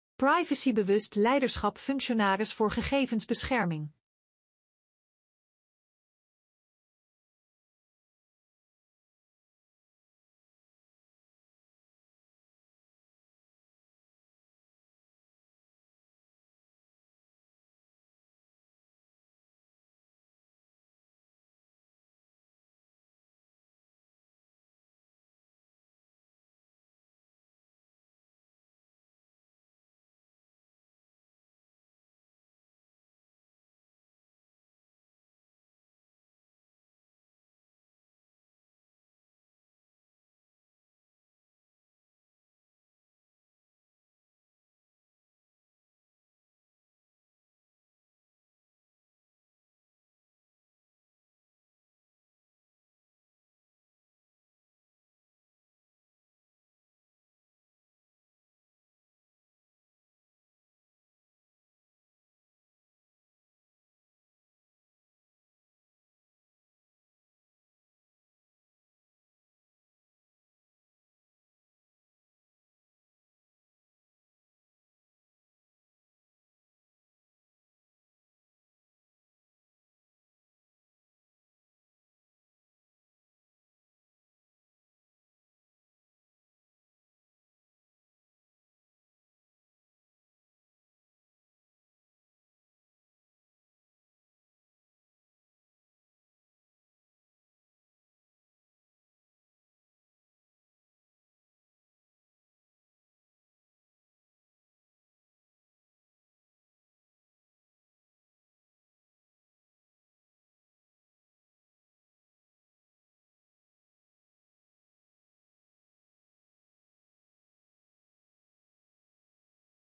Voice-over: